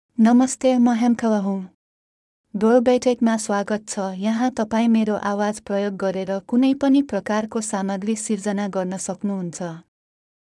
Hemkala — Female Nepali AI voice
Hemkala is a female AI voice for Nepali (Nepal).
Voice sample
Listen to Hemkala's female Nepali voice.
Female
Hemkala delivers clear pronunciation with authentic Nepal Nepali intonation, making your content sound professionally produced.